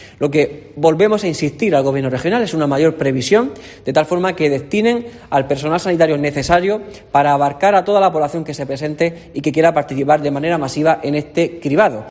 INFORMATIVO MATINAL LORCA VIERNES